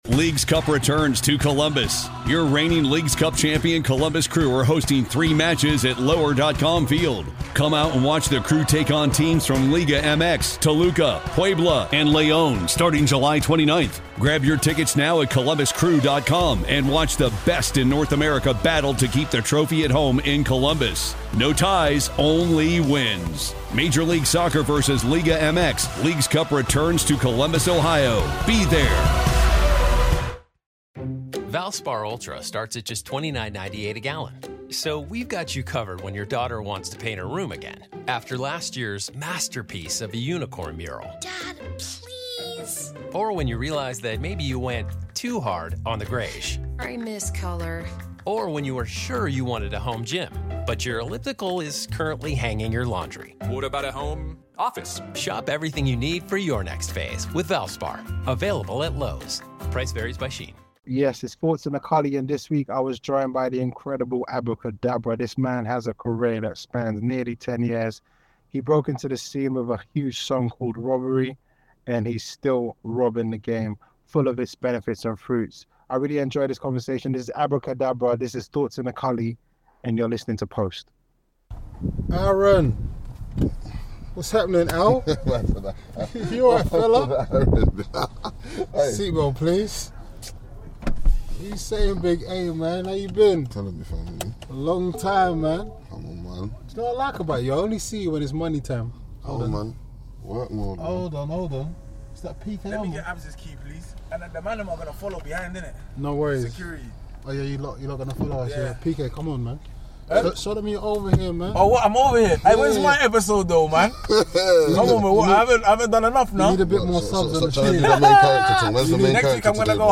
As always with Thoughts In A Culli, the in-depth interview is filled with great stories and an honesty from the artist you wouldn’t get anywhere else.